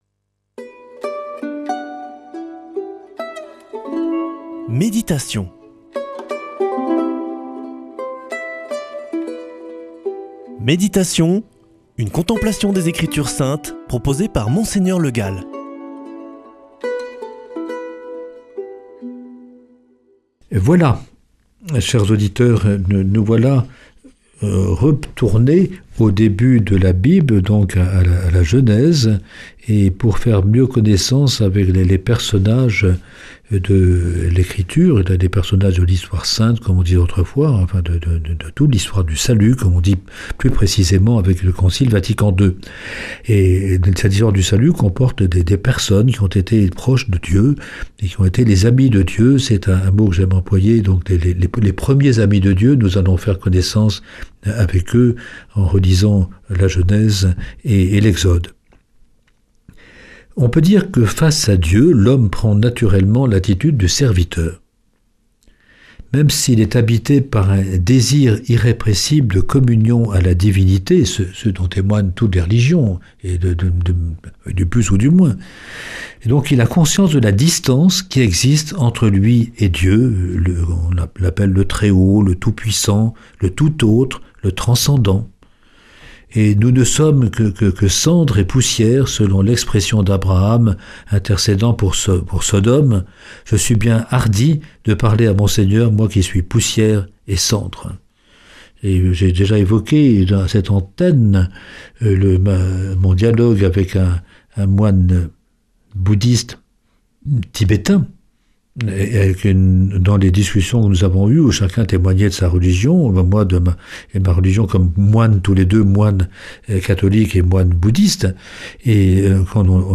[ Rediffusion ] L’attitude fondamentale du serviteur
Présentateur